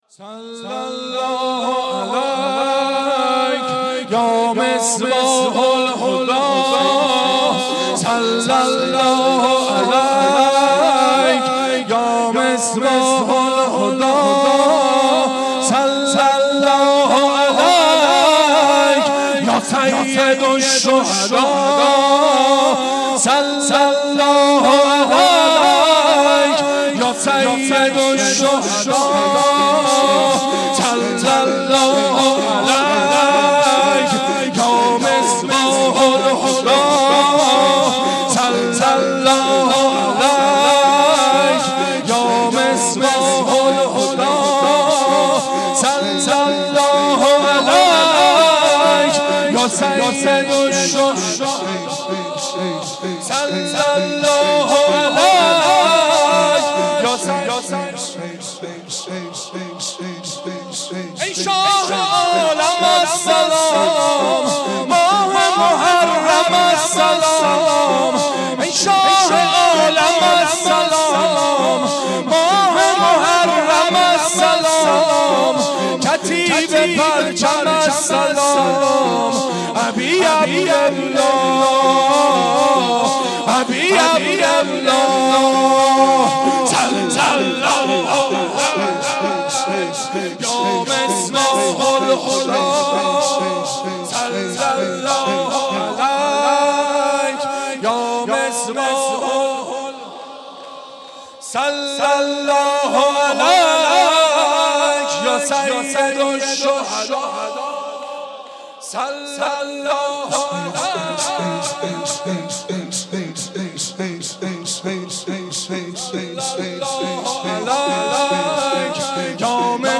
واحد شور
محرم 95